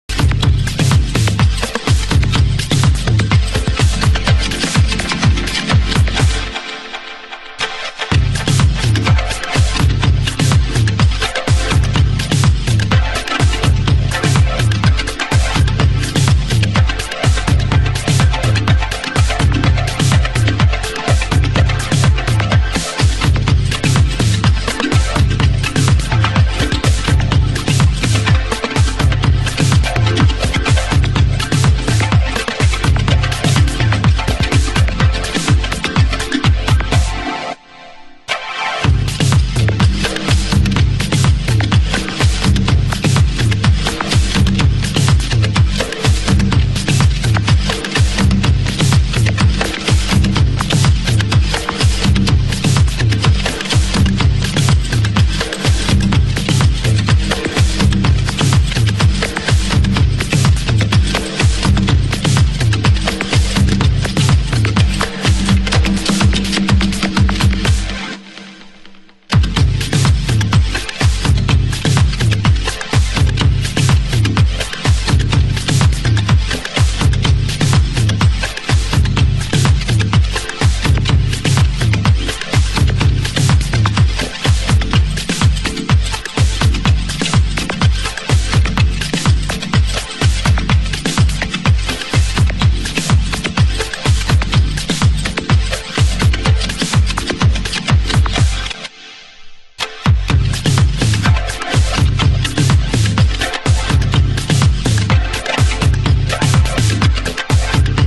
★DISOCDUB NUHOUSE
盤質：少しチリパチノイズ有